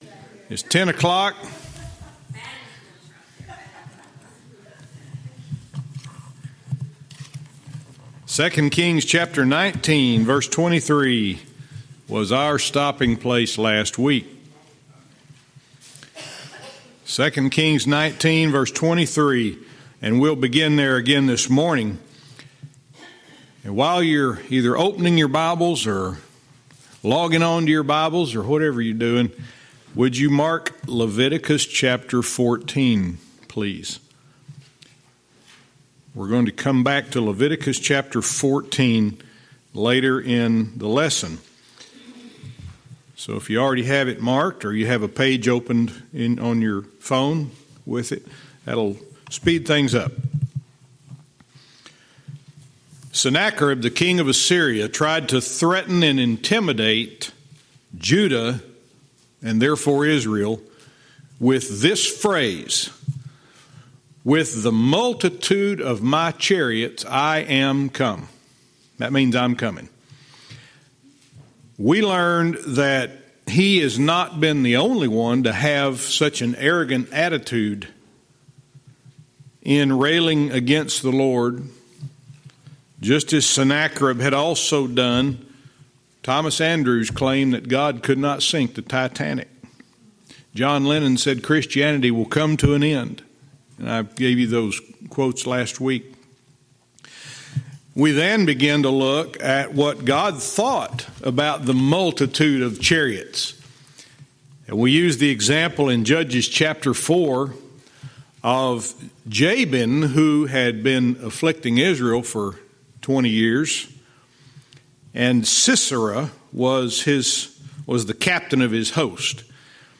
Verse by verse teaching - 2 Kings 19:23(cont)